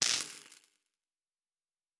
pgs/Assets/Audio/Sci-Fi Sounds/Electric/Spark 16.wav at master
Spark 16.wav